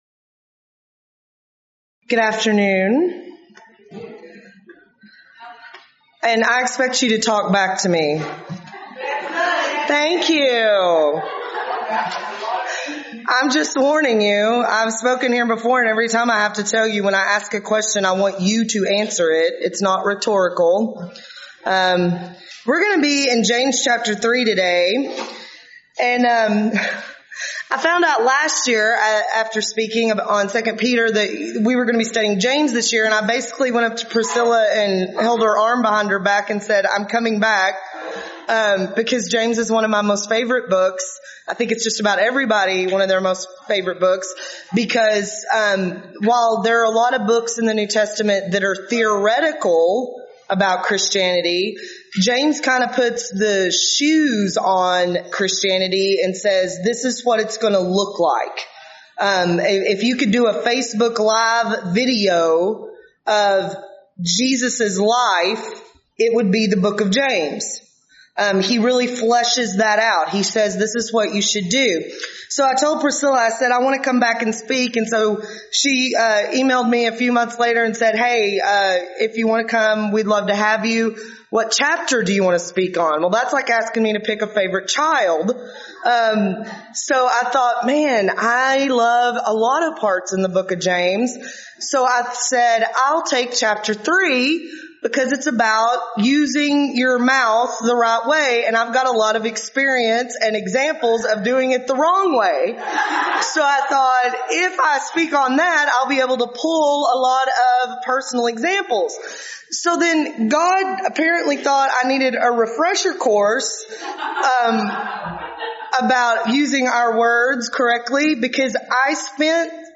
Event: 5th Annual Texas Ladies In Christ Retreat Theme/Title: Studies in James
lecture